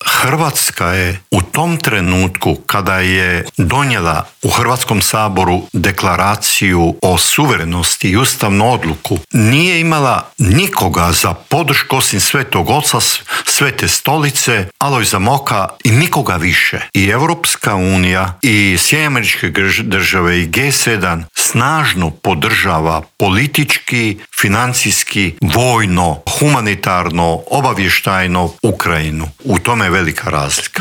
ZAGREB - Povodom drugog izdanja knjige ‘Diplomatska oluja - sjećanja najdugovječnijeg Tuđmanovog ministra‘, u Intervjuu tjedna Media servisa gostovao je bivši ministar vanjskih poslova i posebni savjetnik premijera Mate Granić.